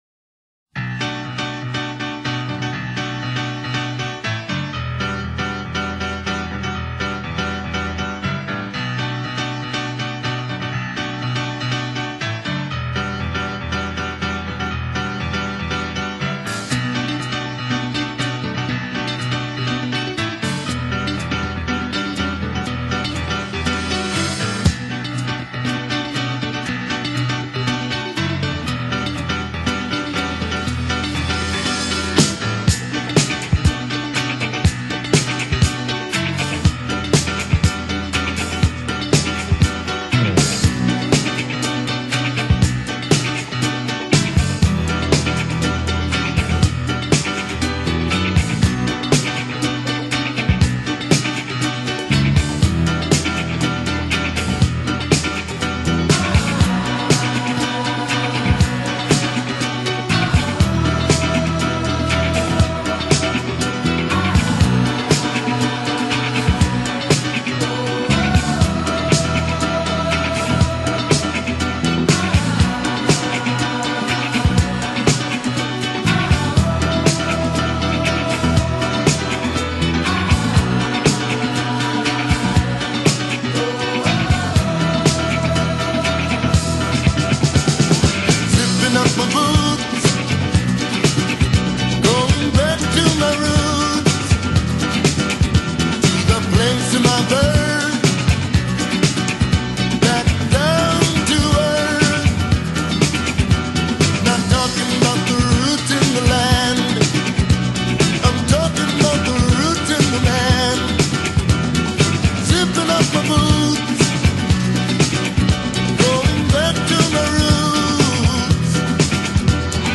Gran pezzo funky degli anni ’80.